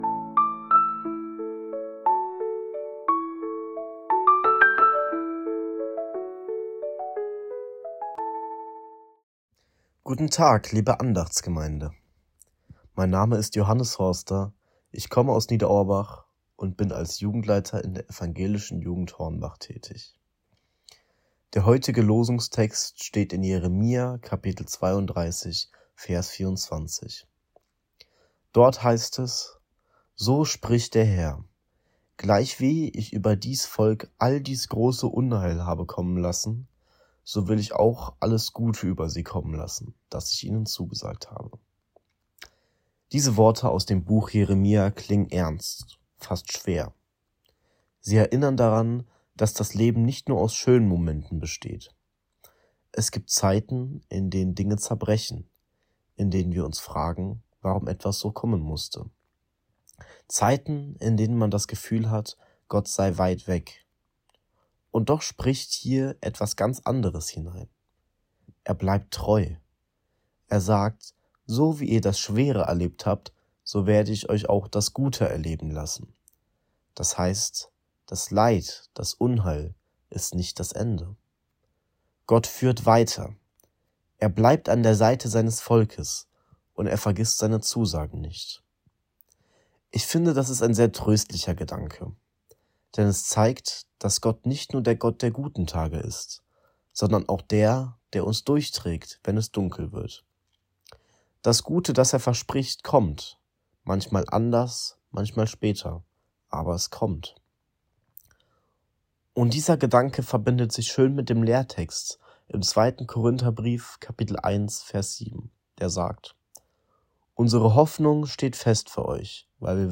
Kategorie: Losungsandacht
Text und Sprecher